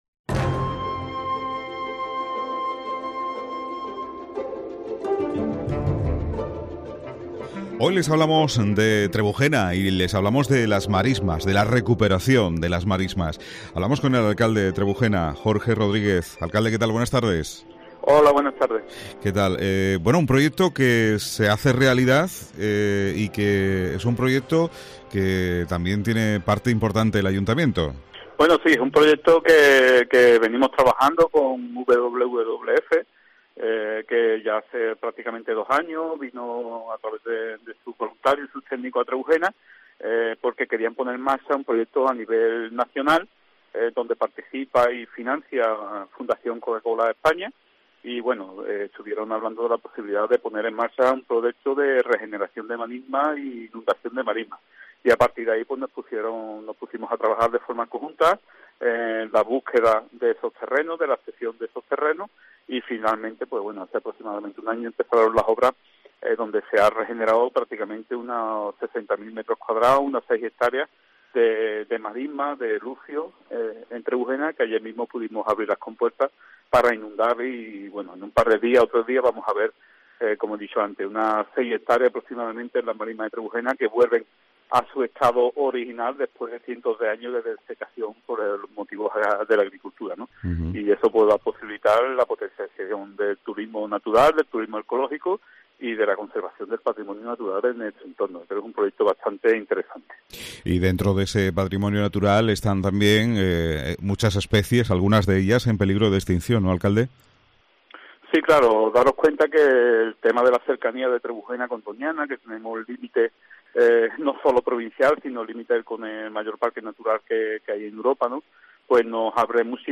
AUDIO: Jorge Rodríguez, Alcalde de Trebujena habla de este proyecto de recuperación del entorno natural del municipio.